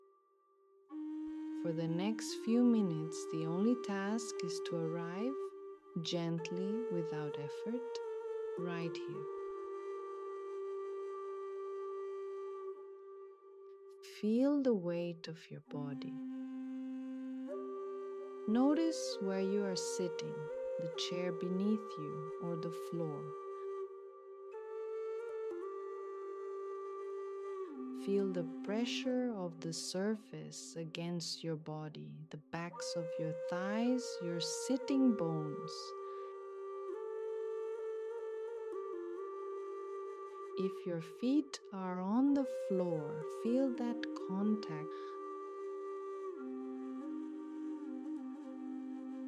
Format: 6-Part Audio Course (12 MP3 Files: 6 Theory Tracks + 6 Guided Practices)
Course-Preview.mp3